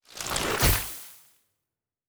Nature Spell 24.wav